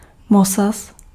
Ääntäminen
US : IPA : /bɹæs/ RP : IPA : /bɹɑːs/